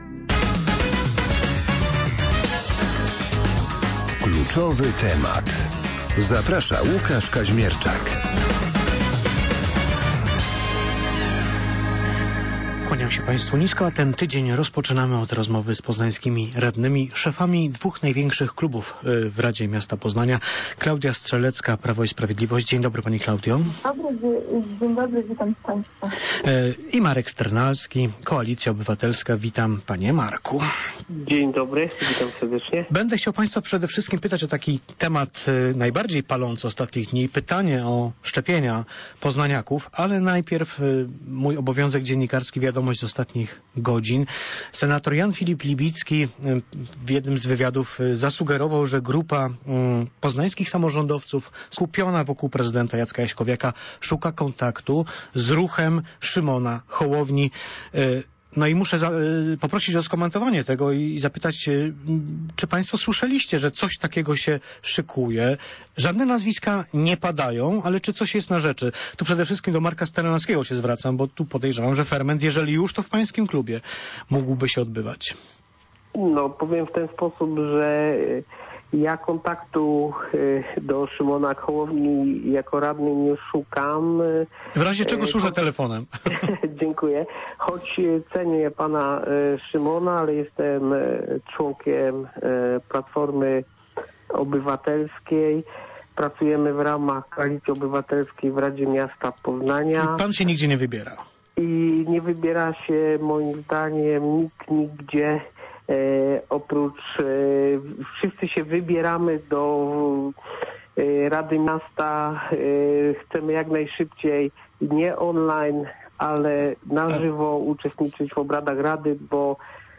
Poznańscy radni: Klaudia Strzelecka i Marek Sternalski - szefowie dwóch największych klubów w Radzie Miasta: PiS i KO byli gośćmi porannej rozmowy "Radia Poznań".